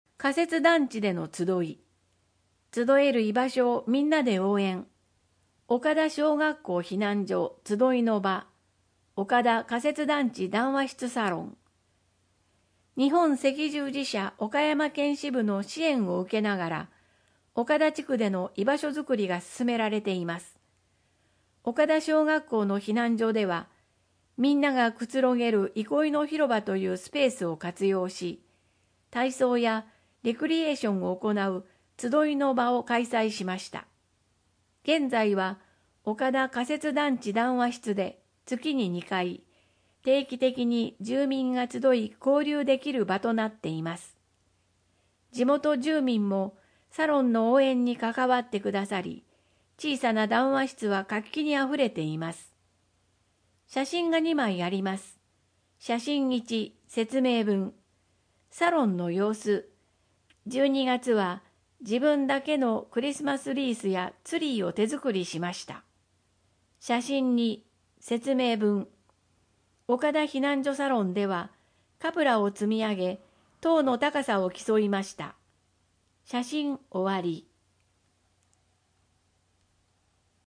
豪雨ニモマケズ（音訳版）